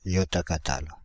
Ääntäminen
Ääntäminen Paris: IPA: [jɔ.ta.ka.tal] France (Île-de-France): IPA: /jɔ.ta.ka.tal/ Haettu sana löytyi näillä lähdekielillä: ranska Kieli Käännökset englanti yottakatal Suku: m .